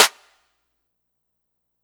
ATL Clap.wav